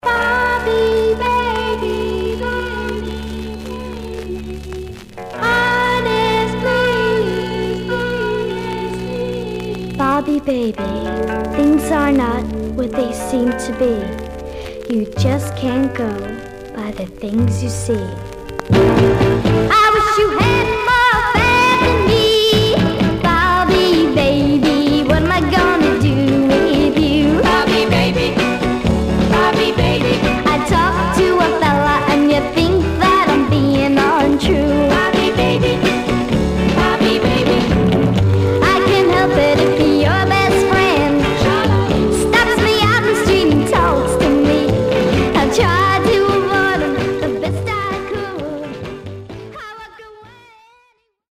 Surface noise/wear Stereo/mono Mono
White Teen Girl Groups